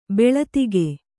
♪ beḷatige